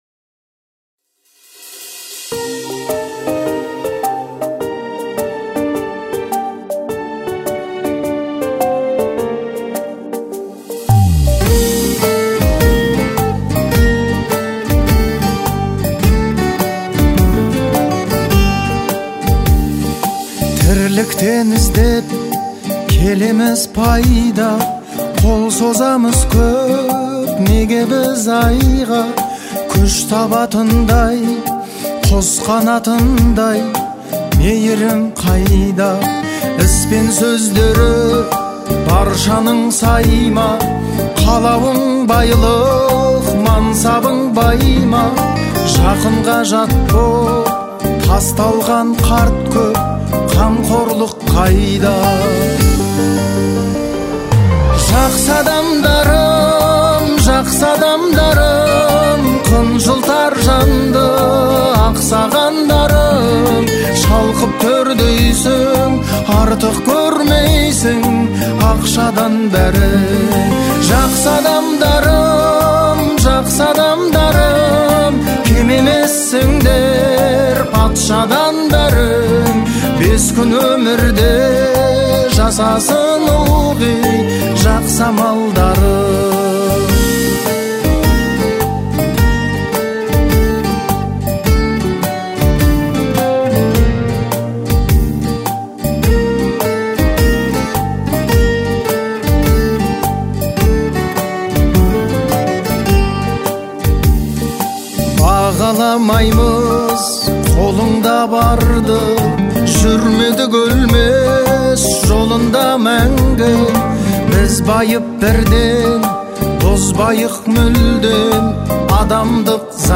это трек в жанре поп, который наполнен теплом и позитивом.